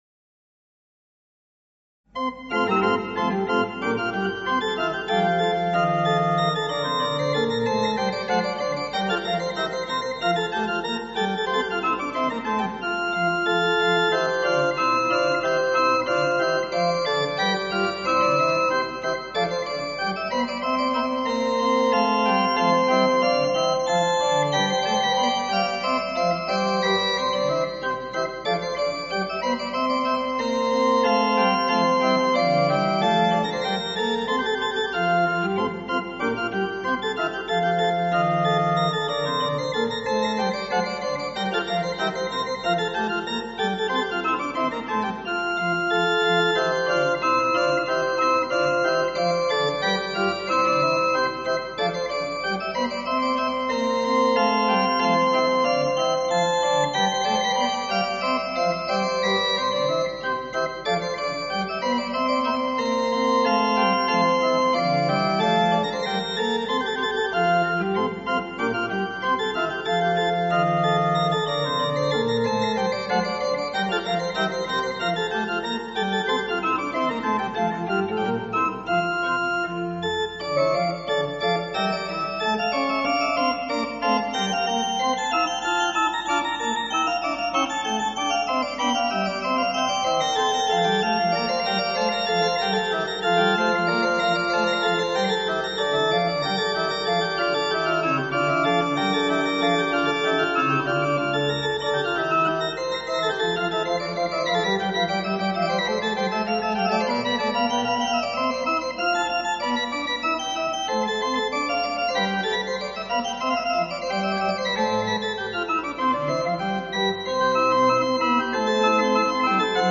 nebo si ukázku positivu